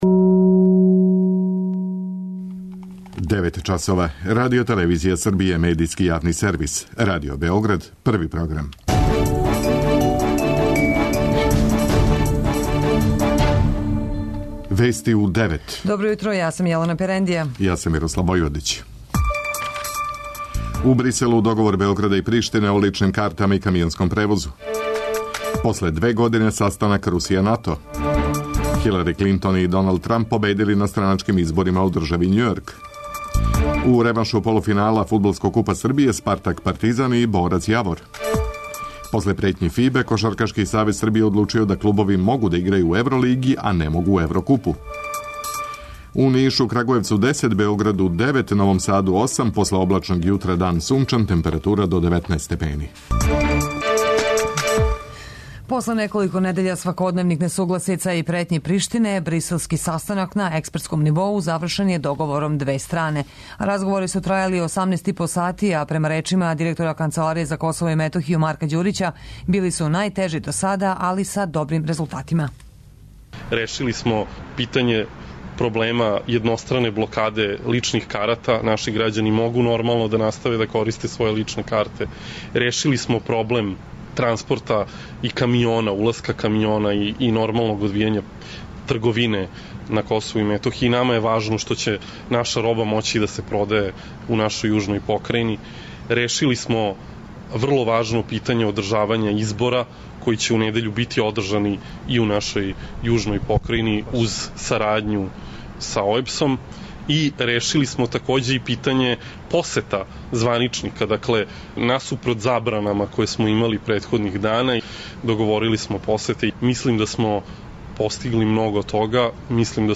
преузми : 10.00 MB Вести у 9 Autor: разни аутори Преглед најважнијиx информација из земље из света.